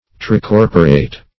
Search Result for " tricorporate" : The Collaborative International Dictionary of English v.0.48: Tricorporal \Tri*cor"po*ral\, Tricorporate \Tri*cor"po*rate\, a. [L. tricorpor; tri- (see Tri- ) + corpus, -oris, body.]